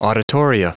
Prononciation du mot : auditoria
auditoria.wav